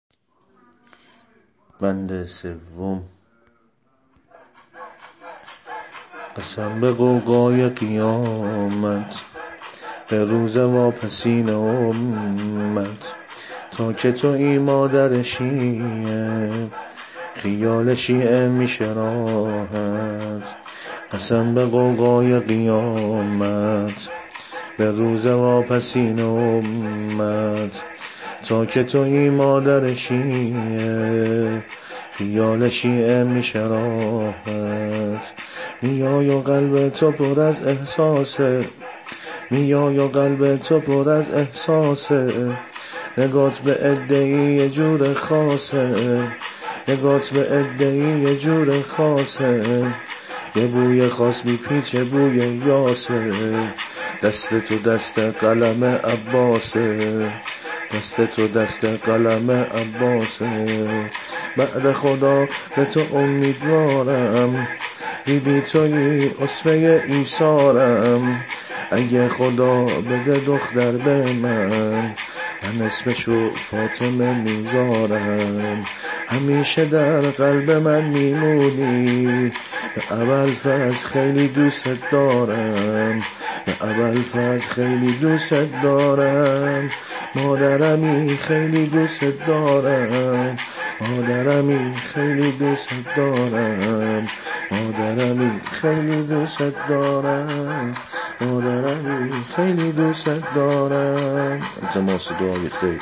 سبک شور حضرت فاطمه زهرا سلام الله علیها -(قسم به آیات الهی)